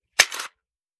Foley / 5.56 M4 Rifle - Magazine Unloading 001.wav